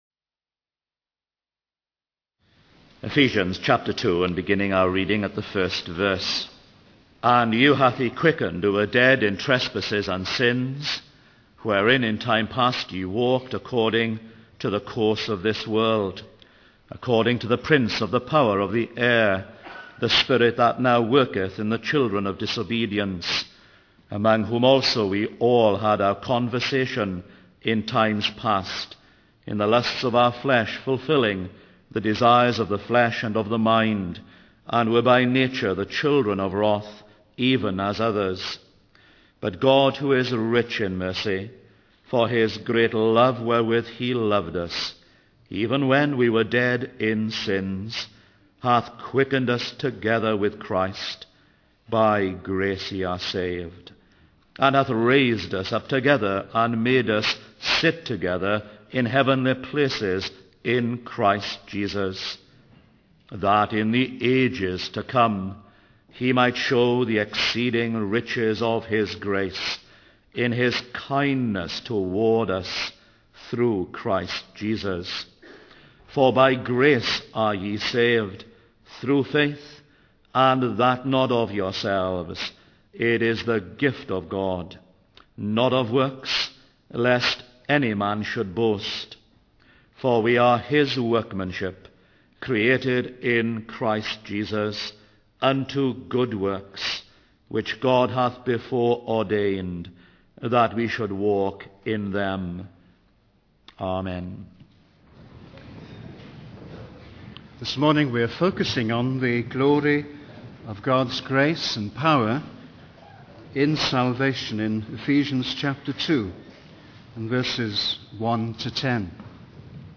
In this sermon, the preacher emphasizes the need for God's intervention in the lives of spiritually dead individuals. He highlights the initiative of God in verses 4, 5, and 6, showing that there is hope for such people.